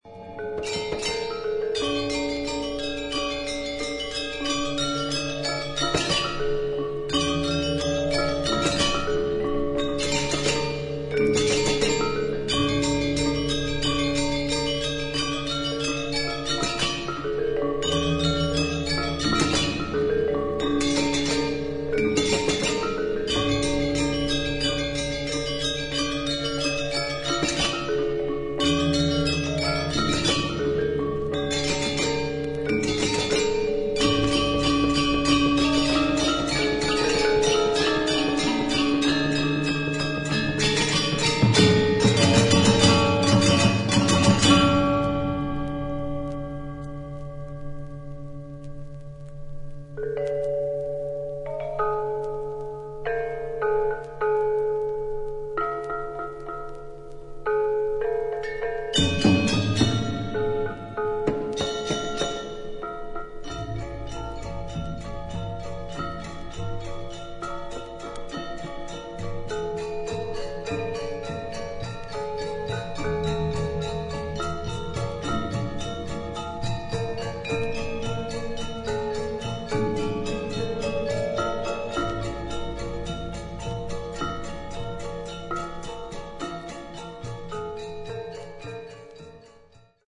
ARTIST : GAMELAN GONG SEKAR ANJAR, GENDER WAJANG QUARTET, DR. MANTLE HOOD